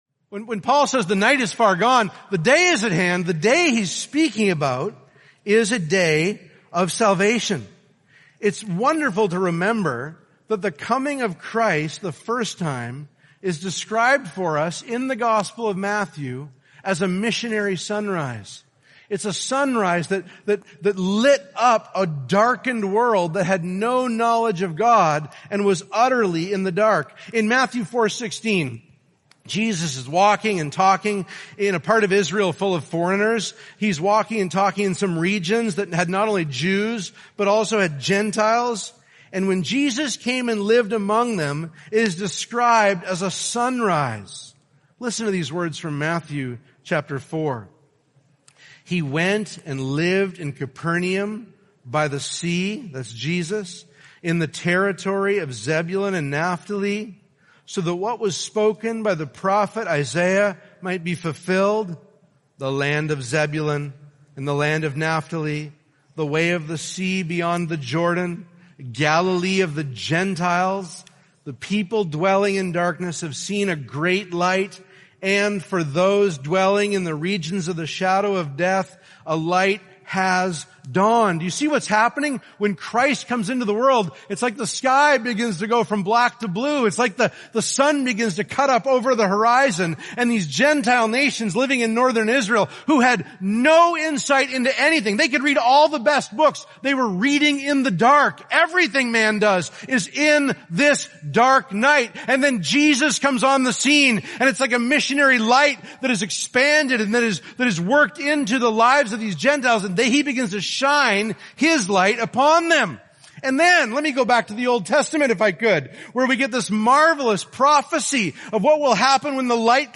2021 Category: Excerpts Topic